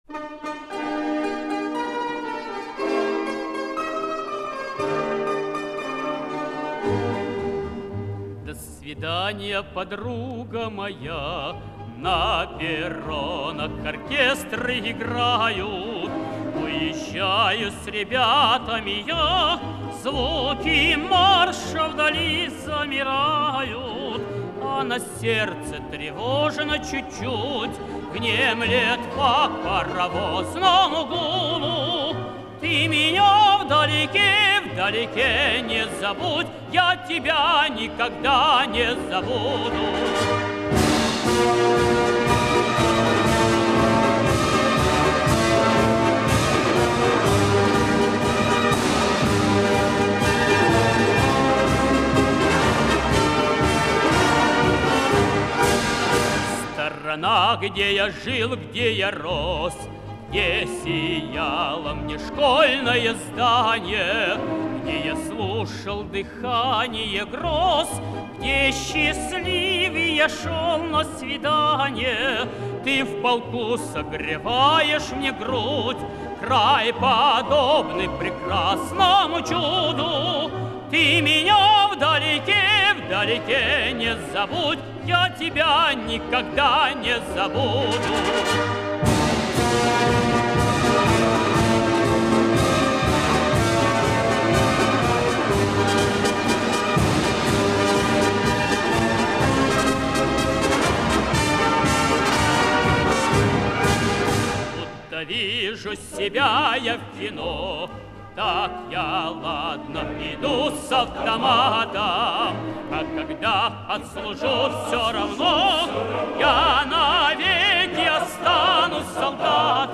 в хор